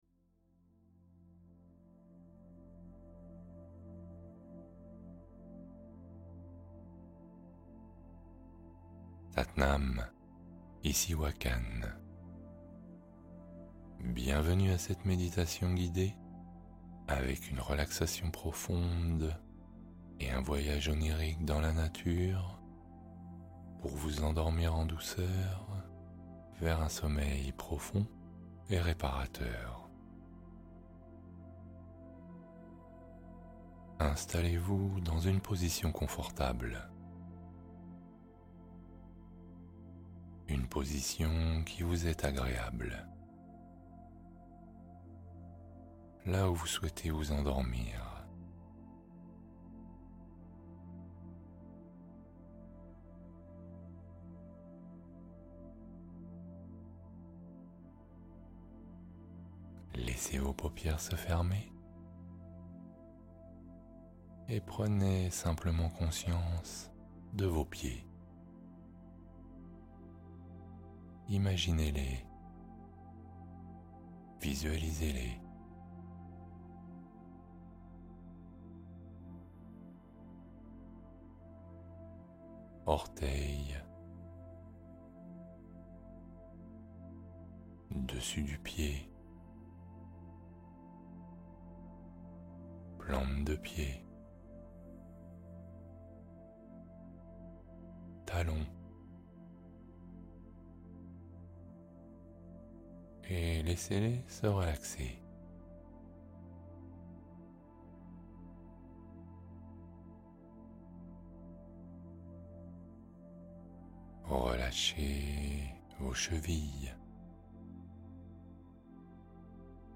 Sommeil Rapide : Méditation pour s'endormir paisiblement en 20 minutes